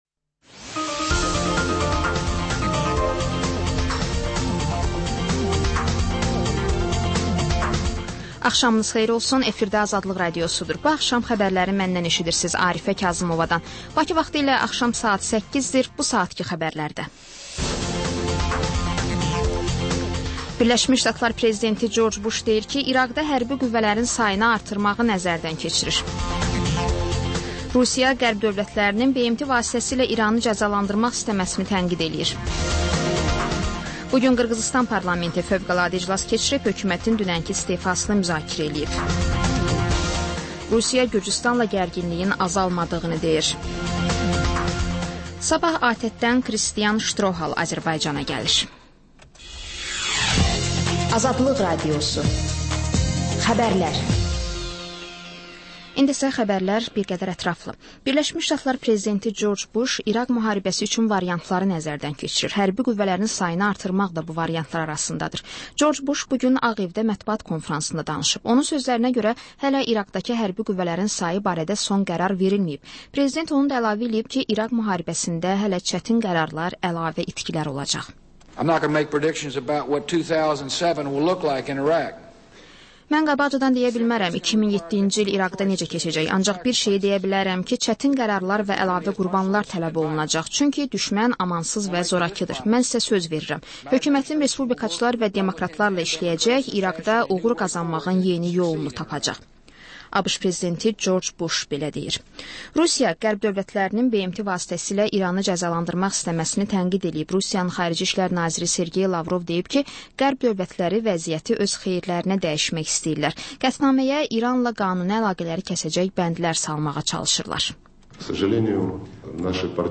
Xəbər, reportaj, müsahibə.